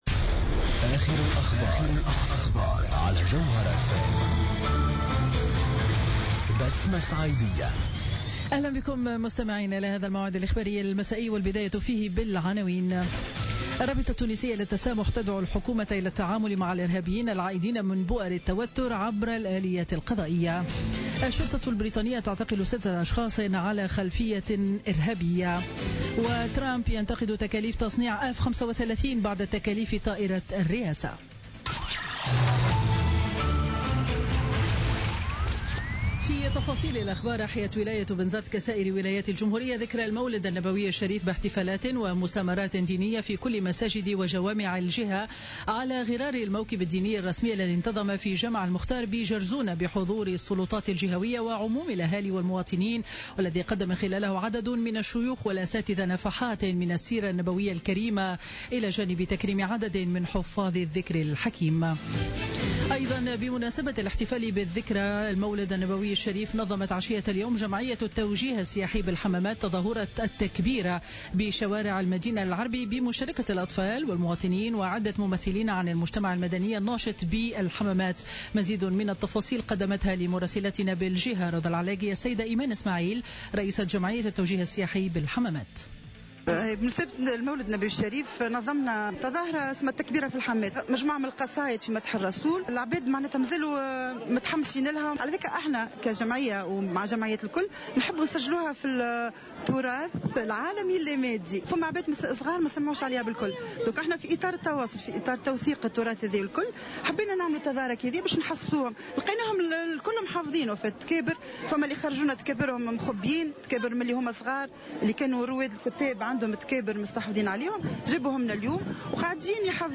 نشرة أخبار السابعة مساء ليوم الاثنين 12 ديسمبر 2016